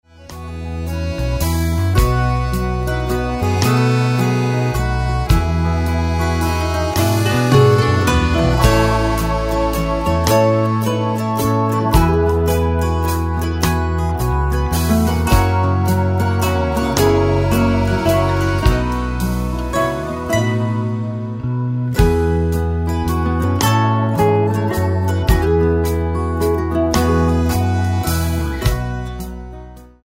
Catholic hymns and songs